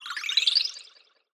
SFX_Magic_Healing_04_fast.wav